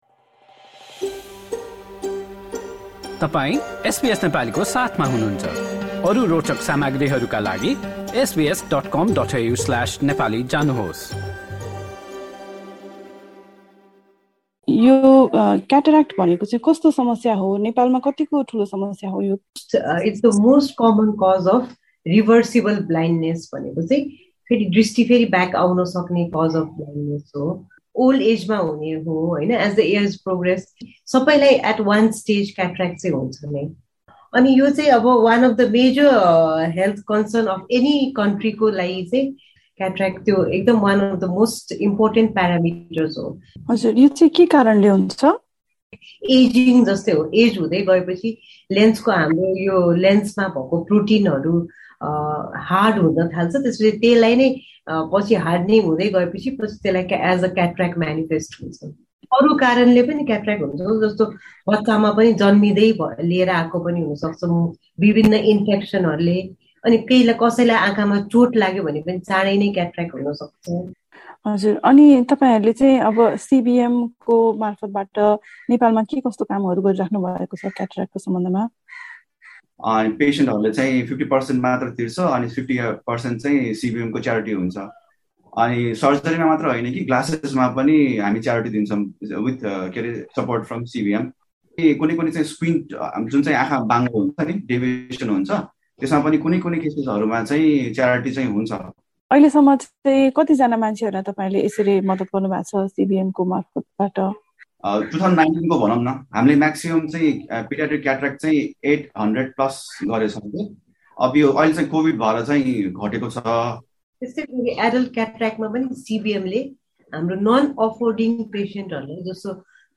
LISTEN TO पुरा कुराकानी सुन्नुहोस्।